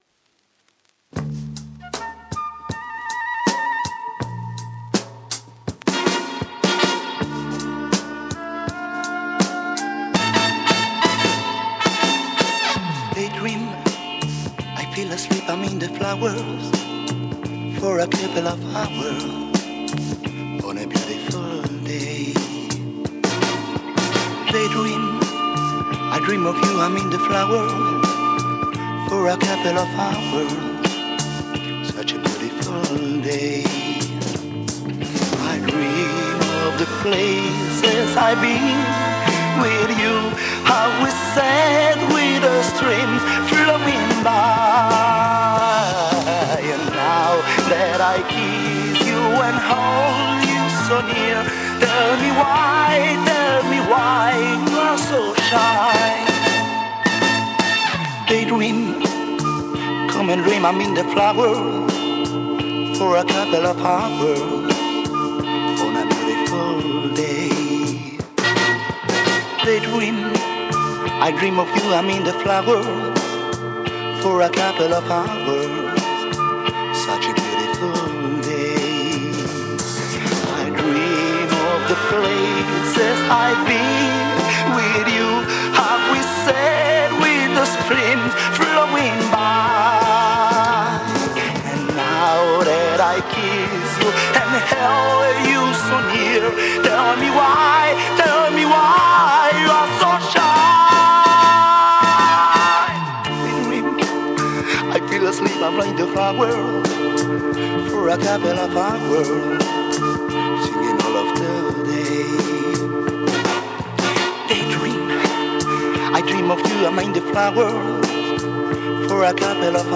Organ Mod dancer album